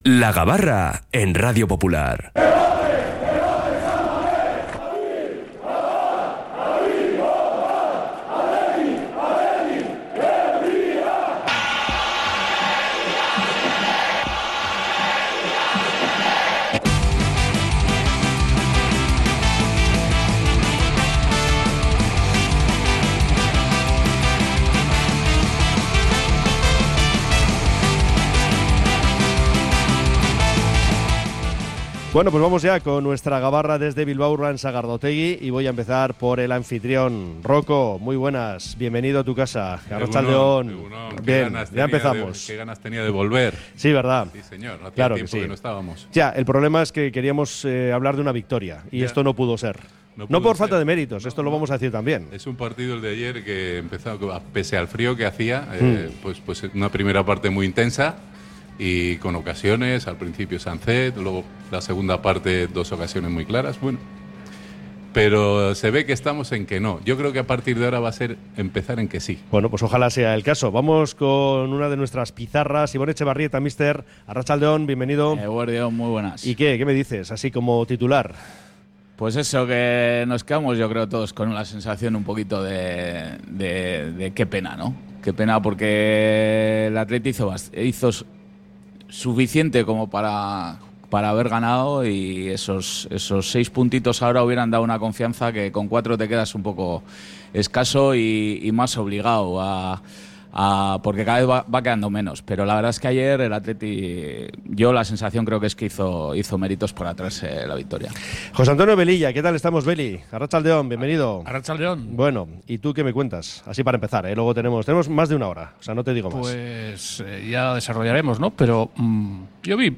Desde Bilbao Urban Sagardotegi hemos analizado toda la actualidad rojiblanca